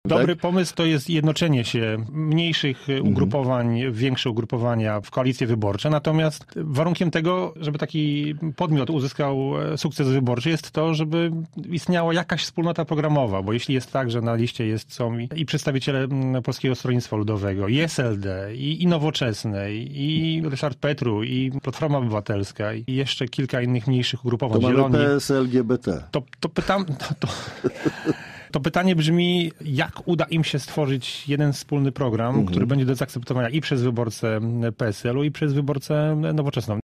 Zdaniem naszego porannego gościa, sama koalicja jest dobrym pomysłem, ale warunkiem jest wspólnota programowa: